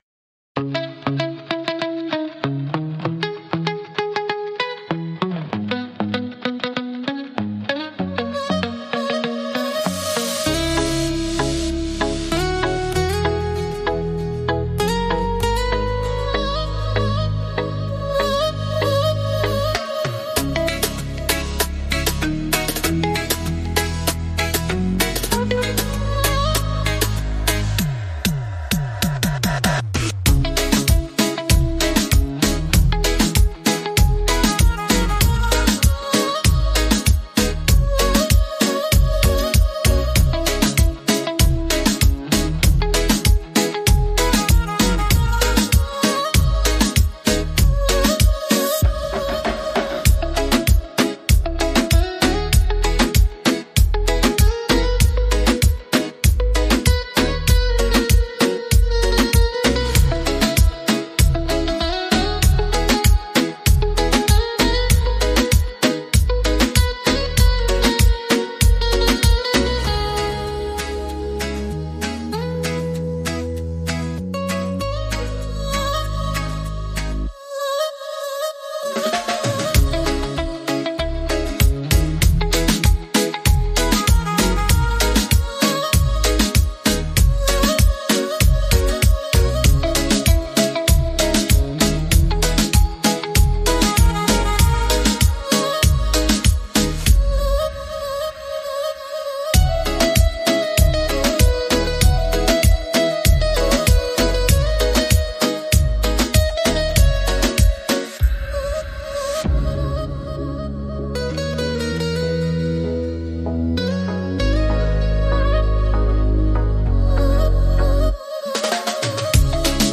Beat Reggaeton Instrumental
Acapella e Cori Reggaeton Inclusi
• Mix e mastering di qualità studio
C#m